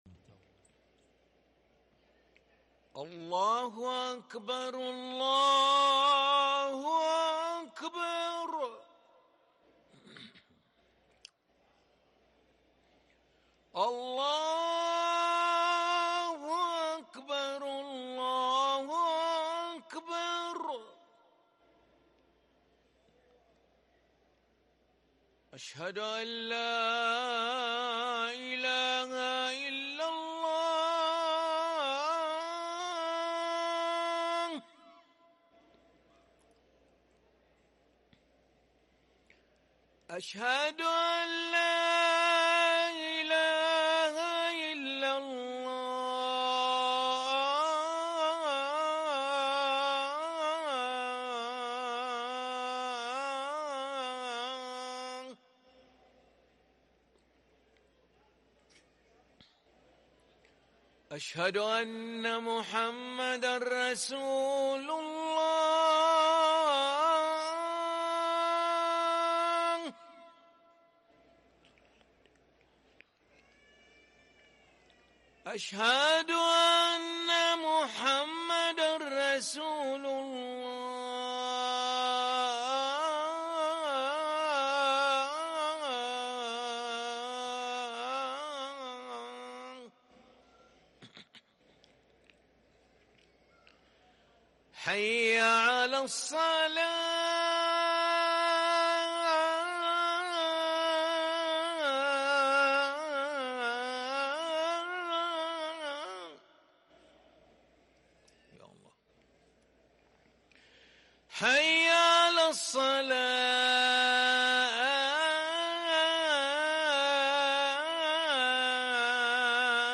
أذان العشاء للمؤذن علي ملا الخميس 20 محرم 1444هـ > ١٤٤٤ 🕋 > ركن الأذان 🕋 > المزيد - تلاوات الحرمين